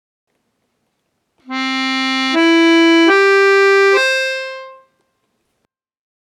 Ás veces esas notas non soan á vez, o fan por separado, dando lugar aos arpexios.
arpegioasc.mp3